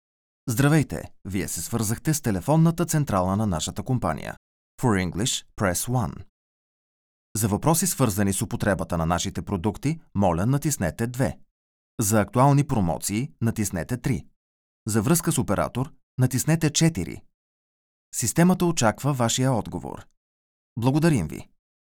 Commerciale, Naturelle, Cool, Chaude, Corporative
Téléphonie